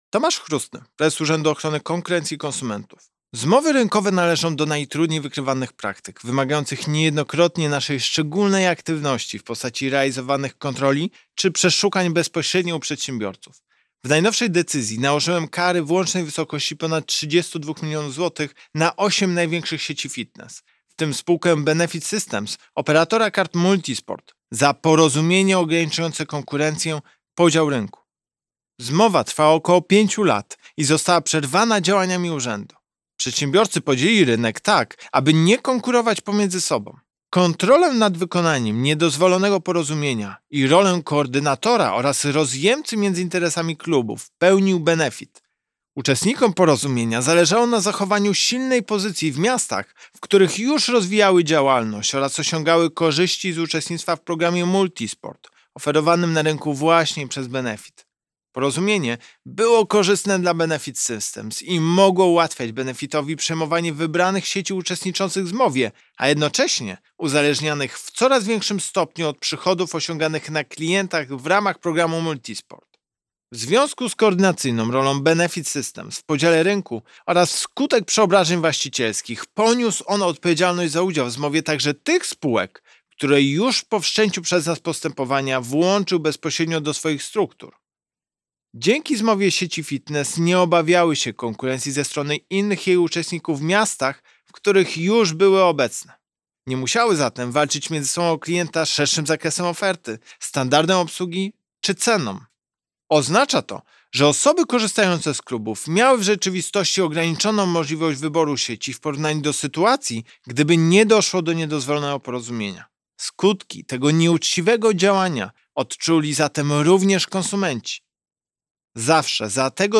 Fitness - Wypowiedź Prezesa UOKiK Tomasza Chróstnego.mp3